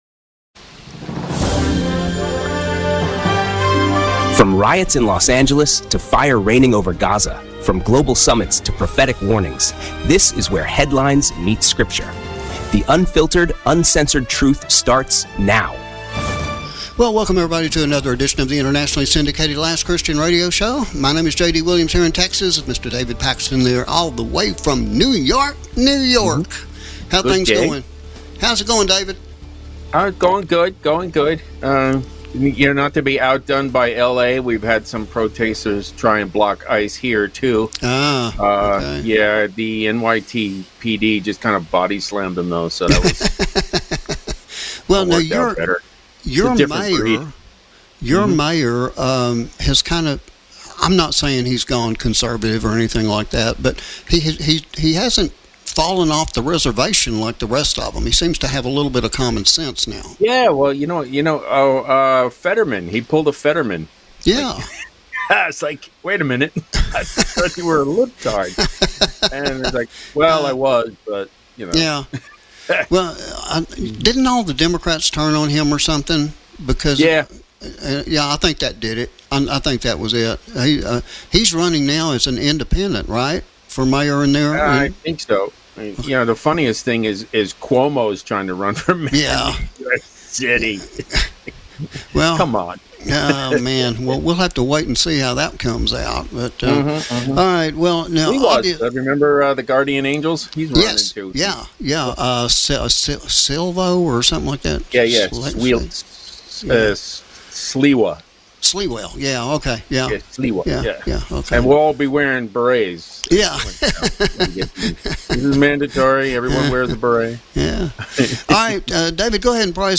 🎙 The Last Christian Radio Show